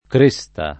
kr%Sta] s. f. — con -e- chiusa in tutta la Tosc., per regolare continuaz. del lat. crista con -i- breve; solo in Garfagnana un’-e- più spesso aperta; e in Roma una pn. oscillante — nessuna diversità di pn., oggi, tra la c. del gallo, la c. dell’elmo, la c. del monte, e la locuz. fam. far la cresta sulla spesa [f#r la kr%Sta Sulla Sp%Sa], «ricavarne di nascosto un avanzo» (alteraz. del fior. di L. Lippi far un po’ d’agresto [f#r um p0 d agr$Sto], con allus. a vendemmia abusiva, e più direttam. del roman. fà l’agresta [f# l agr$Sta], frainteso nell’800 come fà la gresta [f# la gr$Sta]) — sim. il cogn. C.